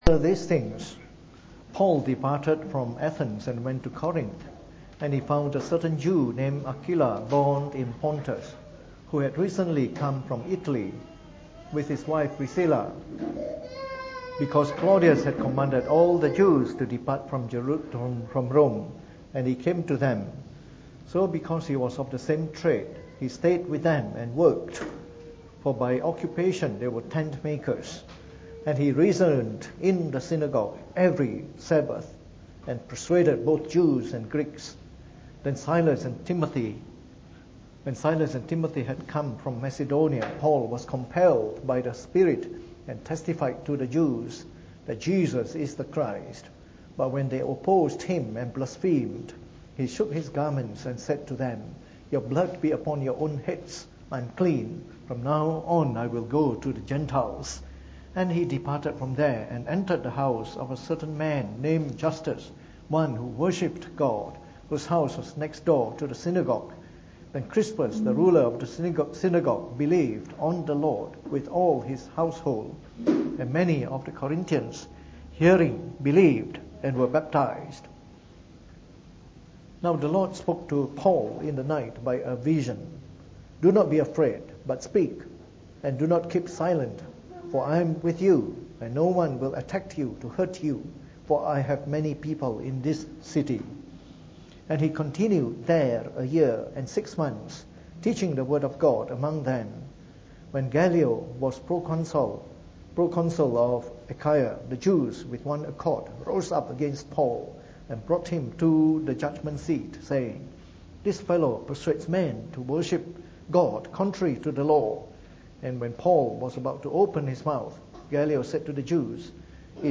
Preached on the 2nd of August 2017 during the Bible Study, from our series on Church Planting Today.